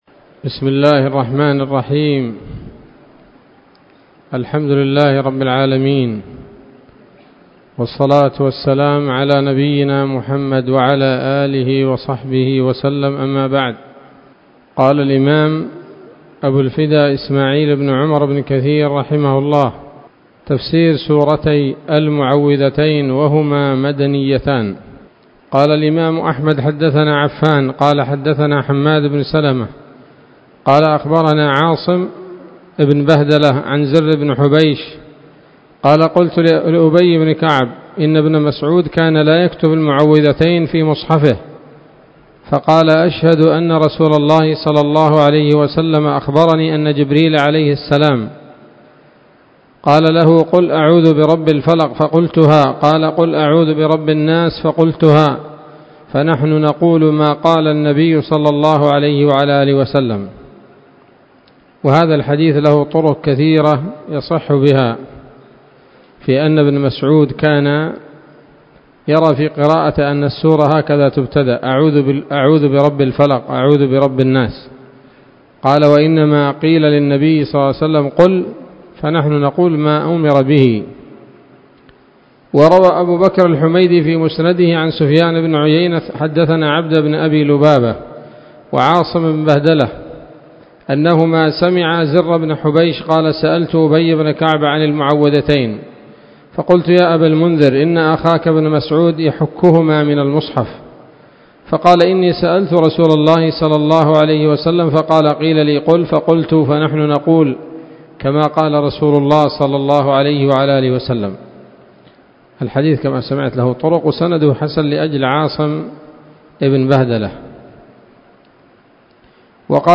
الدرس الأول من سورة الفلق من تفسير ابن كثير رحمه الله تعالى